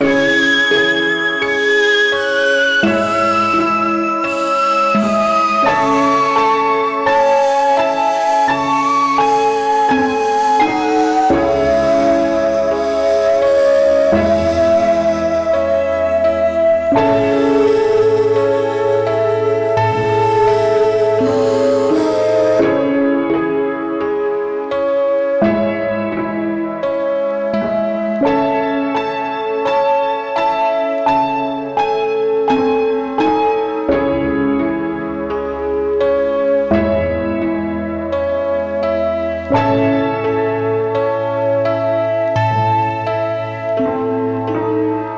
描述：用Omnisphere合成器编辑的方舟陷阱环路。
Tag: 85 bpm Trap Loops Synth Loops 7.62 MB wav Key : C Logic Pro